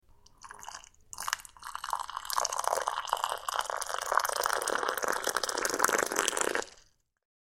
Звуки молока
Заливаем хлопья молоком